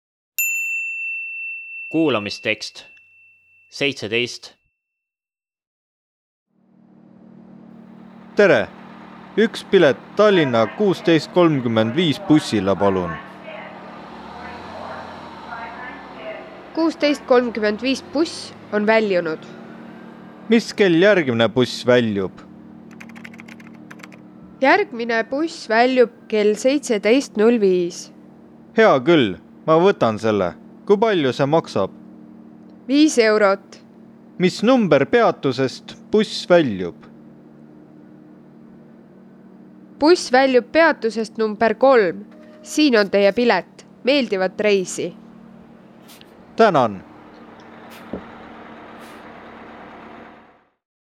Kuulamistekstid eesti keele töövihikule „Suhtleme Eestis A1.2“ on mõeldud eesti keele kui võõrkeele õppijale, kes alustab nullist ning soovib saavutada A1 keeleoskustaseme. CD sisaldab 28 audio peatükki (audiotrack) vastavalt kuulamisülesannete arvule, millest osad koosnevad veel mitmetest dialoogidest ja sõnavarast, kokku 32 audioteksti.